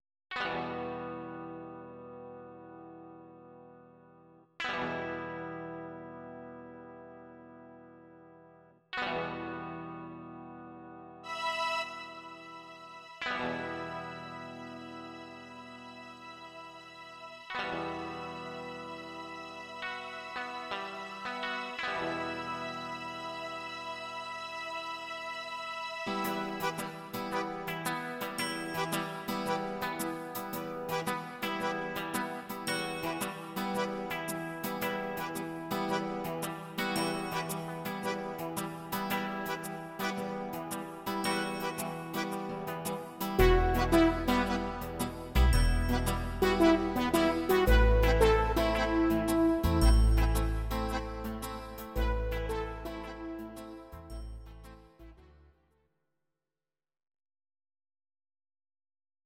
Audio Recordings based on Midi-files
Instrumental